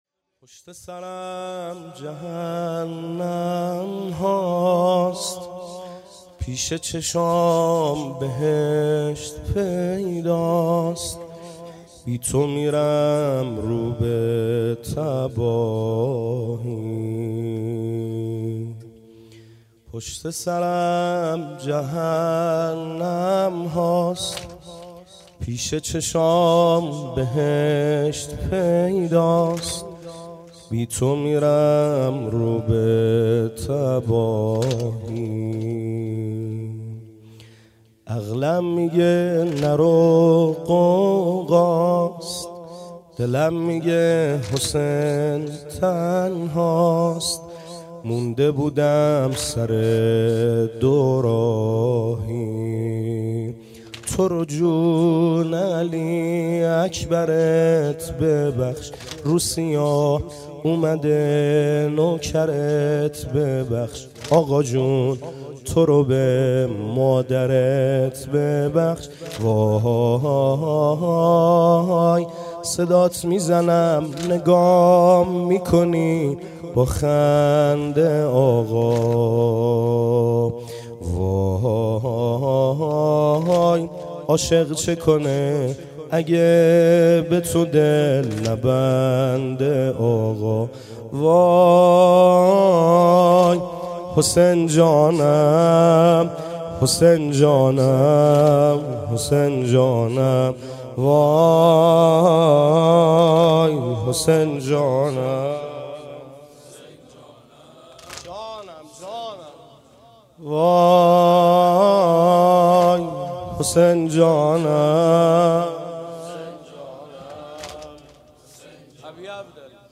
گزارش صوتی شب چهارم محرم 98 | هیأت محبان حضرت زهرا سلام الله علیها زاهدان